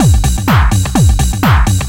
DS 126-BPM A03.wav